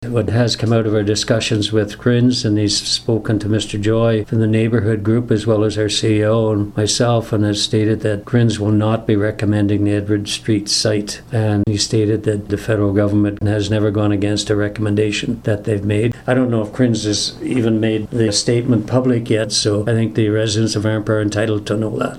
At Arnprior Council Monday, Mayor Walter Stack updated the community on discussions with (and about) Rogers Communications- which has apparently had its eyes on a piece of property at Edward and William Streets for the past several years.